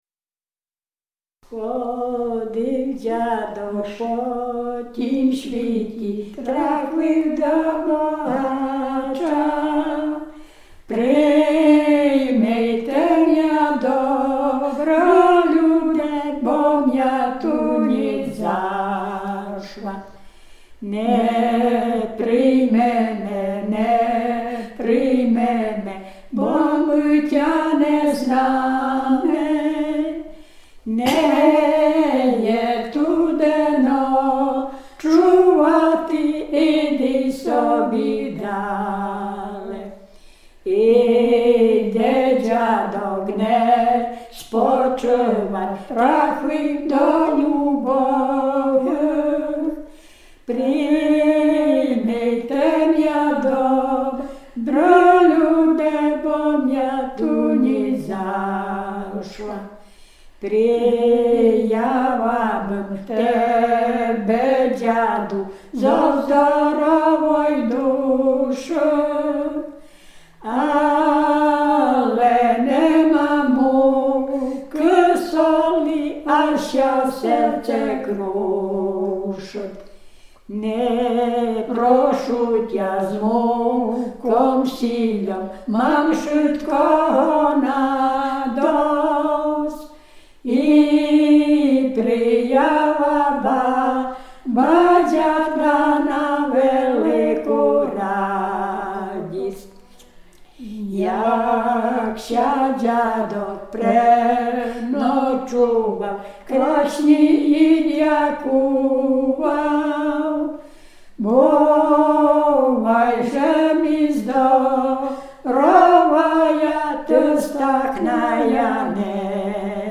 Dolny Śląsk, powat legnicki, gmina Kunice, wieś Piotrówek
Pieśń śpiewana także przy pogrzebach.
Ballada
pogrzebowe nabożne dziadowskie ballady